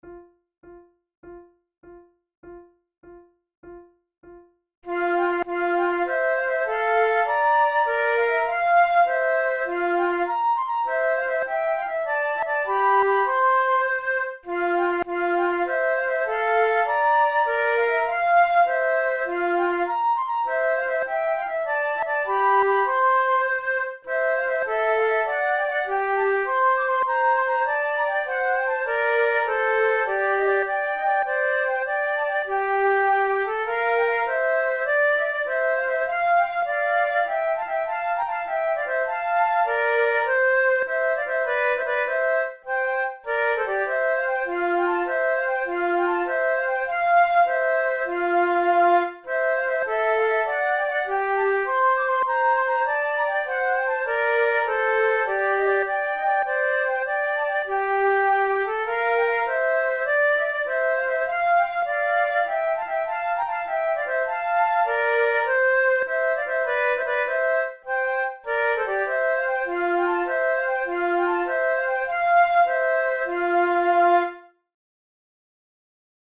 KeyF major
Time signature6/8
Tempo100 BPM
Baroque, Jigs, Sonatas, Written for Flute